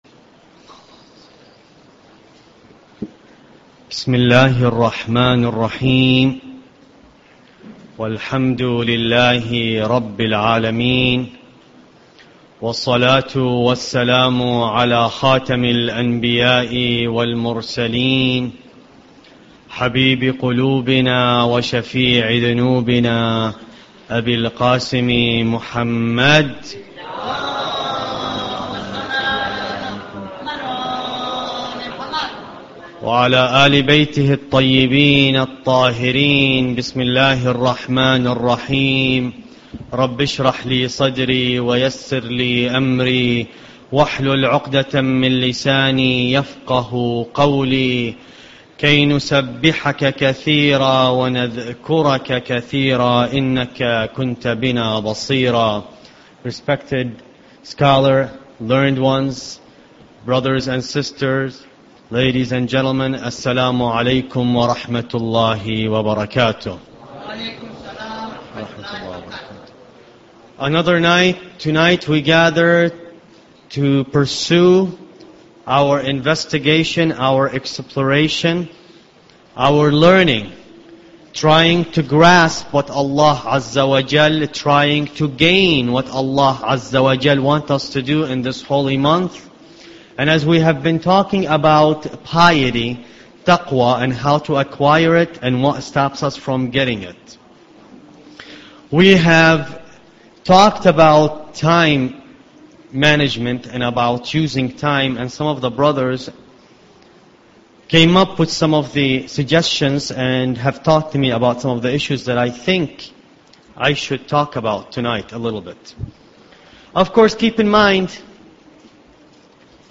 Ramadan Lecture 9